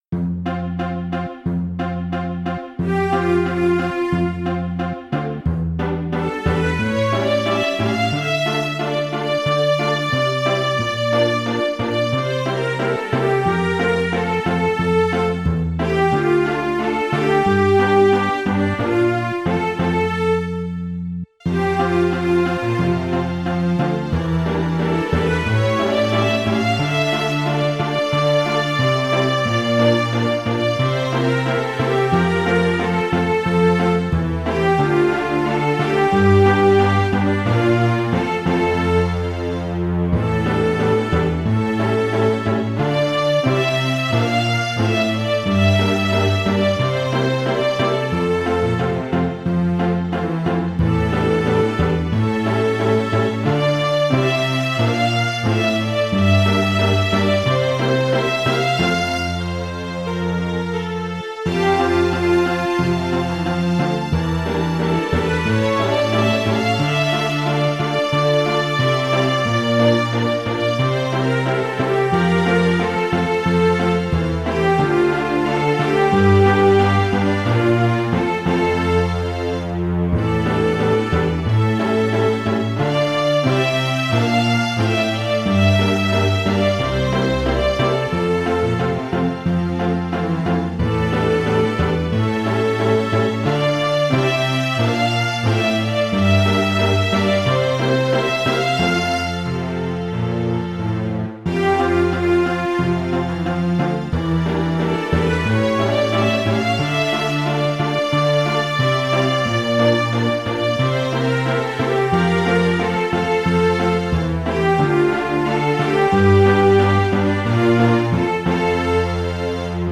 Cello Double Bass Tympani
Drums Percussion Tuned Percussion
Piano Harpsichord Hammond Organ
Synthesizer Classical Guitar Electric Guitar